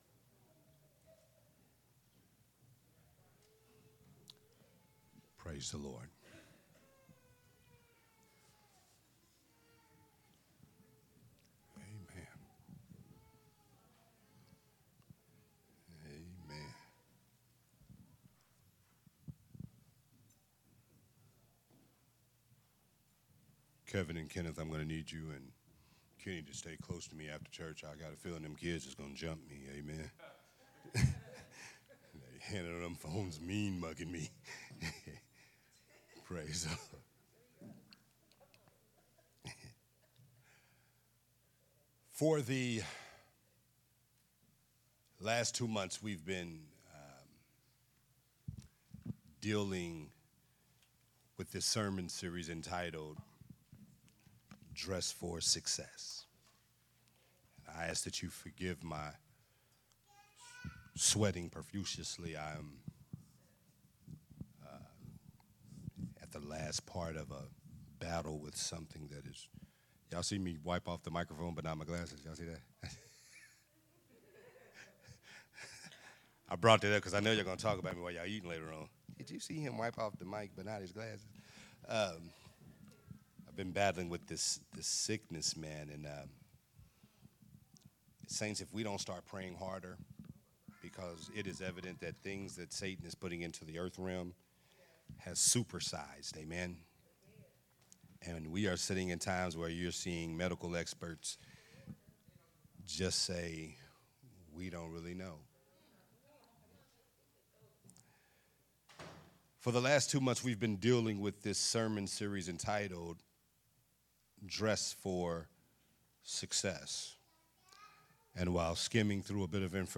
recorded at Unity Worship Center on March 3rd, 2024.